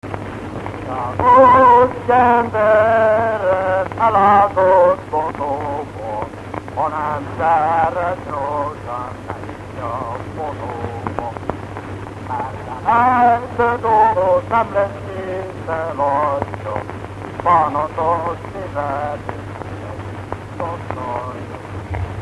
Erdély - Csík vm. - Ajnád
ének
Stílus: 6. Duda-kanász mulattató stílus
Szótagszám: 6.6.6.6